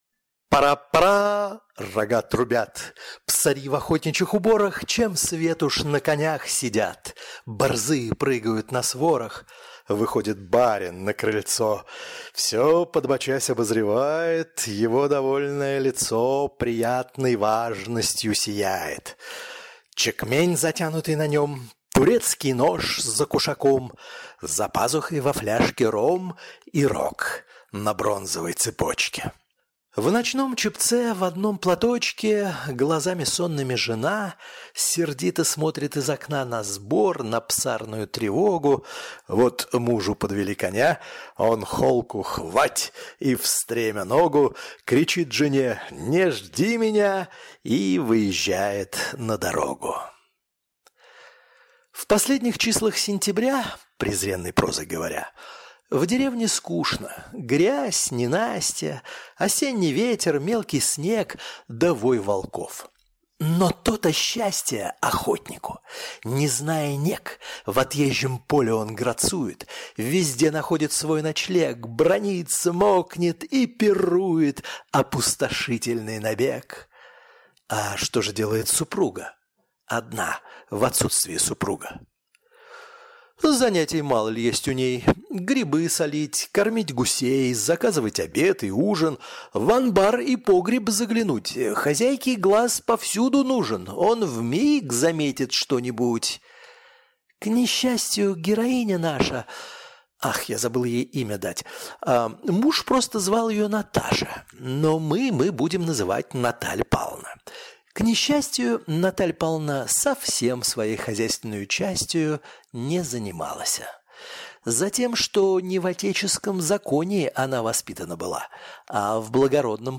Аудиокнига Граф Нулин | Библиотека аудиокниг